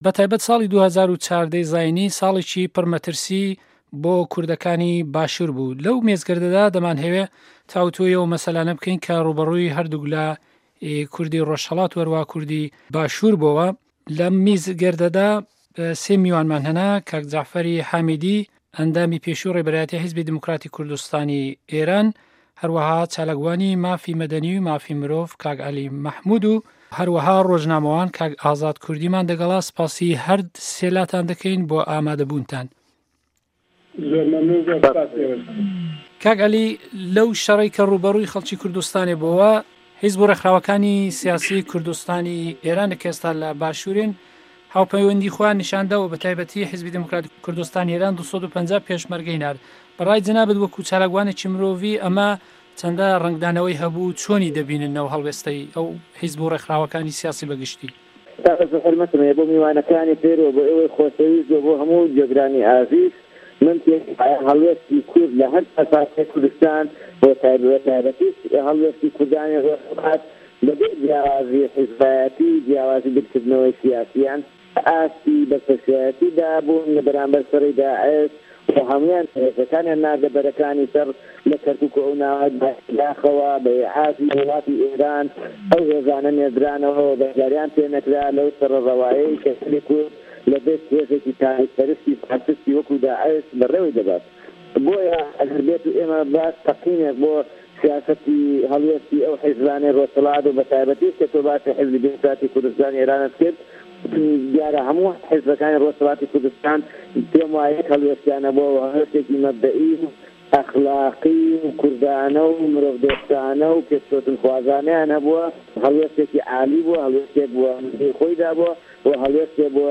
مێزگرد_حکومه‌تی هه‌ریم و کوردستانی ئێران